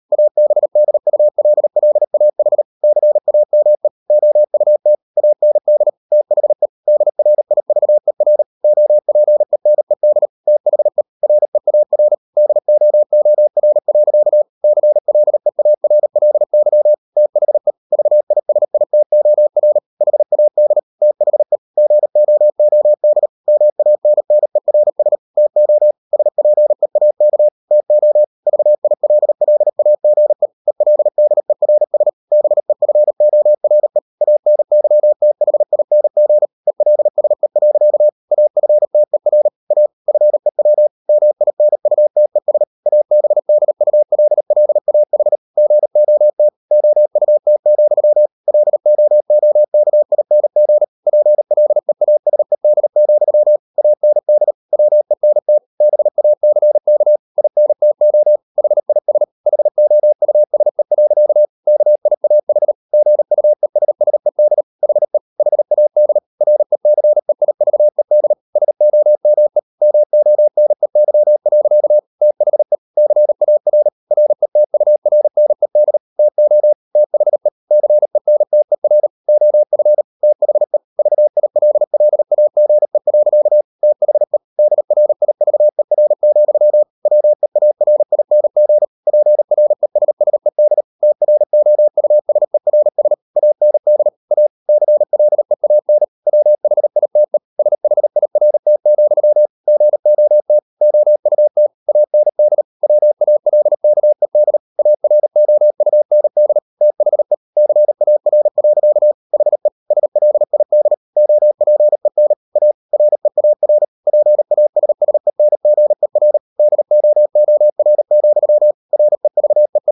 Never 38wpm | CW med Gnister